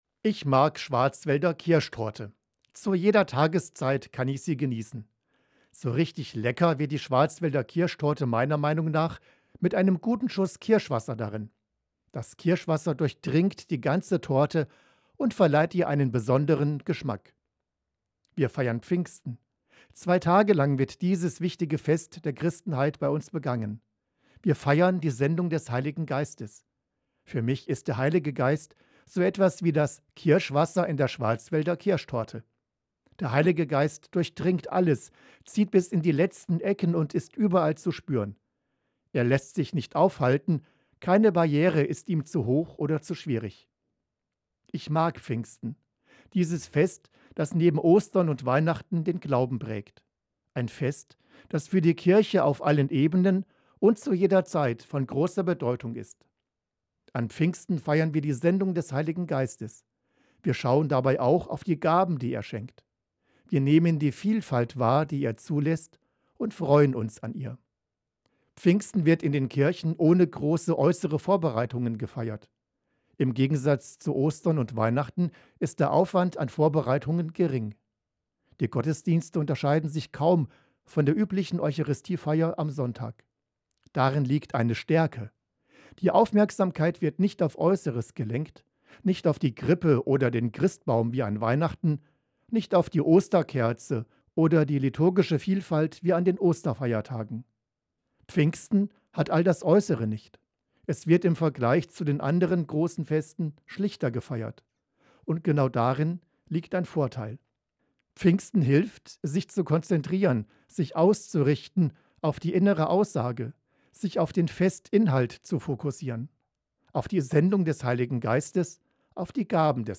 MORGENFEIER